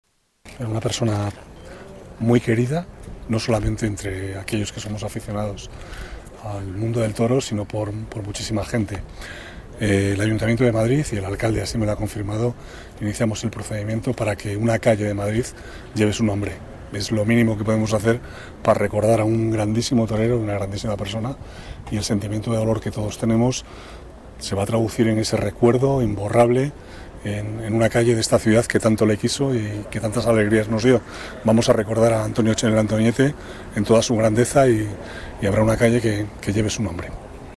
Nueva ventana:Declaraciones vicealcalde, Manuel Cobo: el Ayuntamiento dedicará una calle a Antoñete